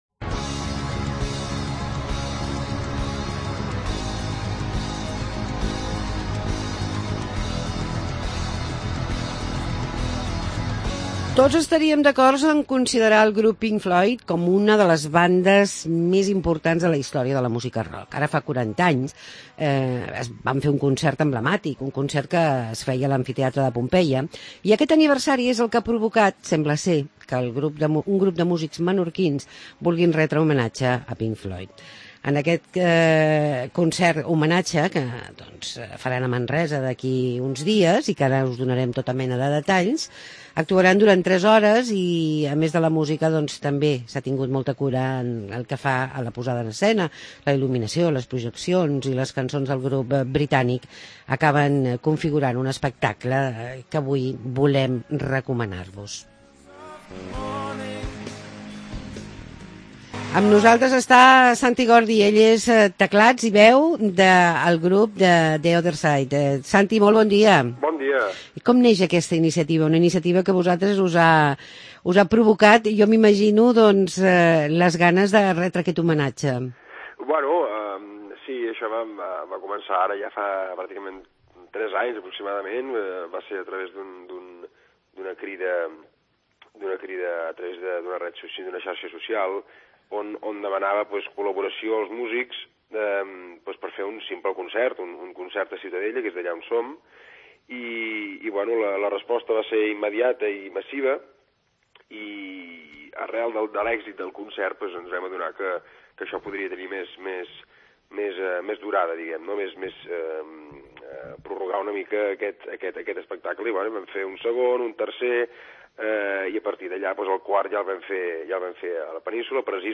AUDIO: Entrevistem al grup "The other side"